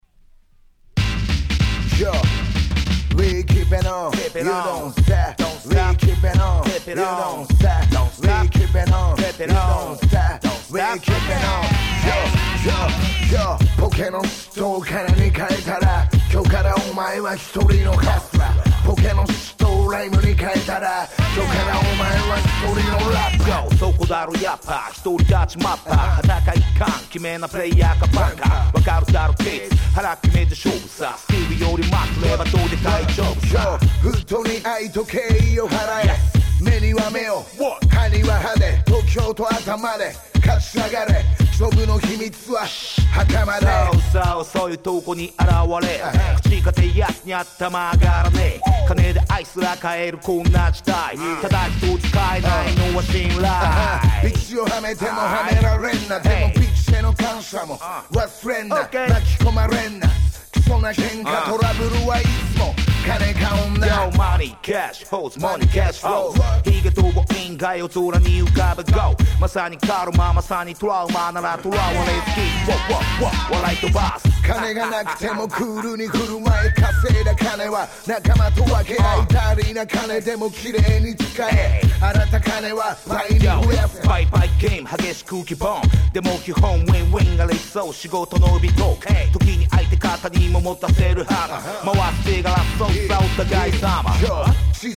13' Japanese Hip Hop Super Classics !!